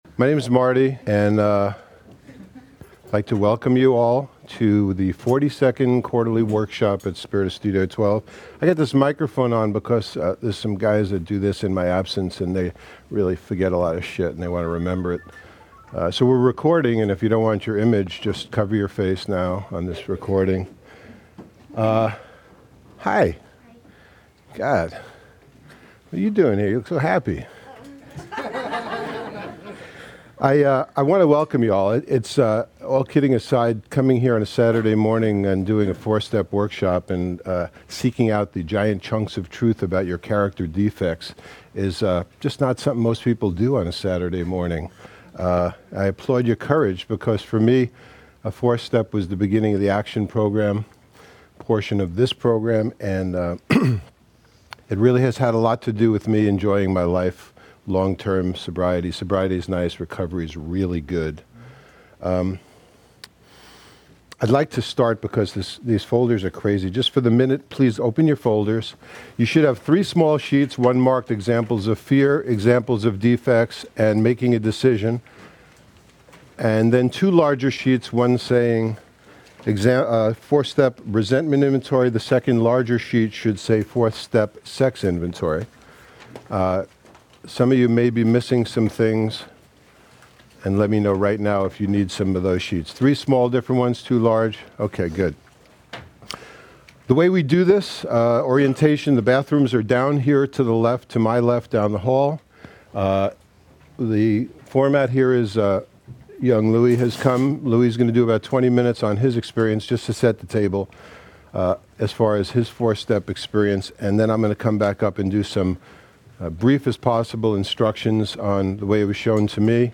Quarterly 4th Step Workshops held at the Spirit of Studio 12